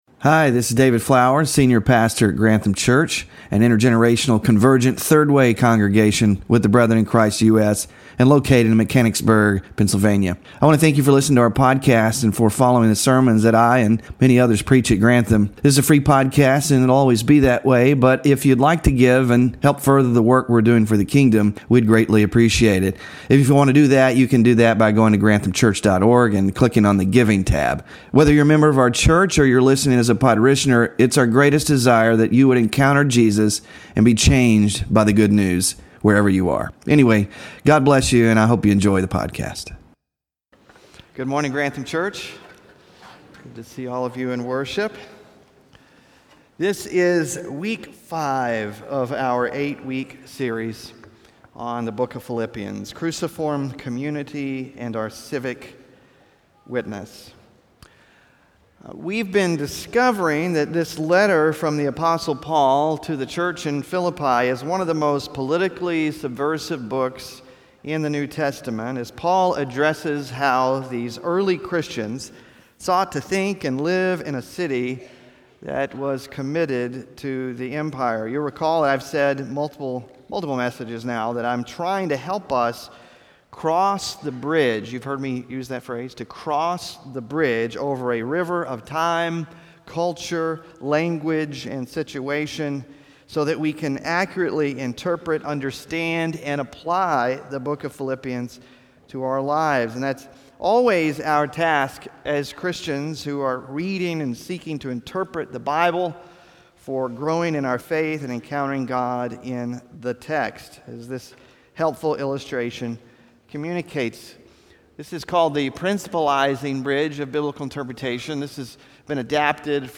PHILIPPIANS SERMON SLIDES (5TH OF 8 IN SERIES) SMALL GROUP DISCUSSION QUESTIONS (9-8-24) BULLETIN (9-8-24)